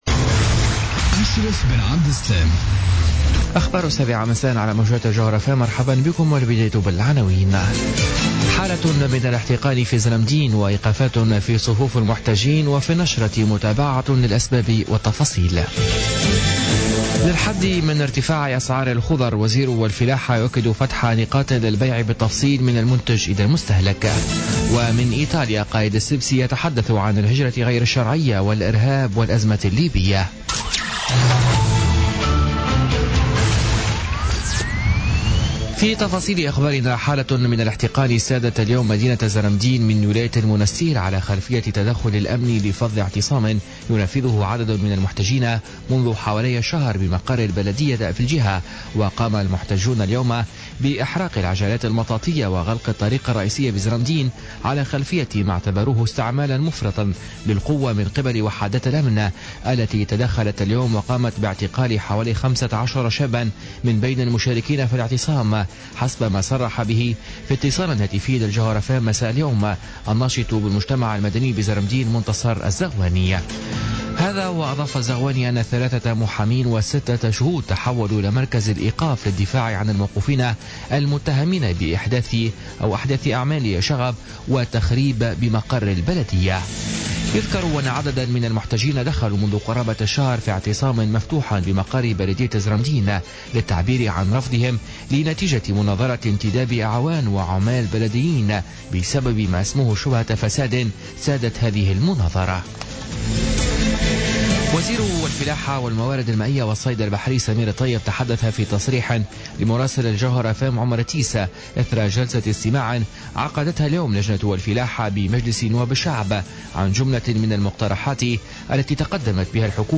نشرة أخبار السابعة مساء ليوم الأربعاء 8 فيفري 2017